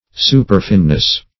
Superfineness \Su"per*fine`ness\
superfineness.mp3